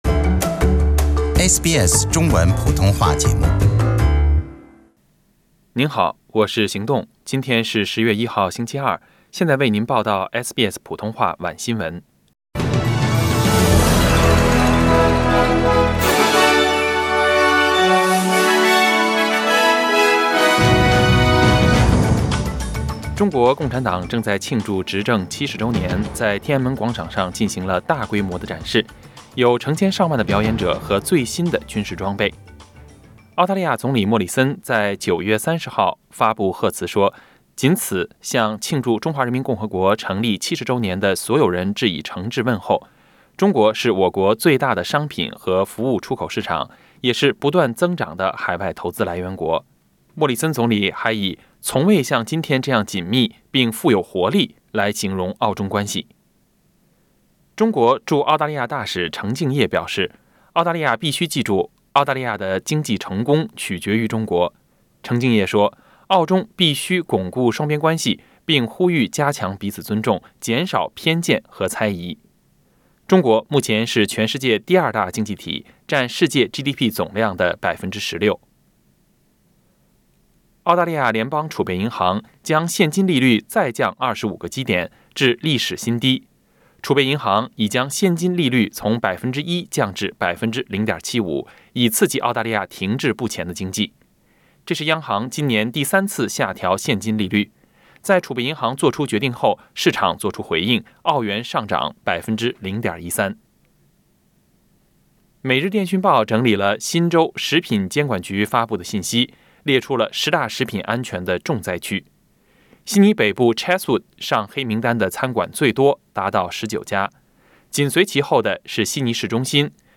SBS晚新闻 （10月1日）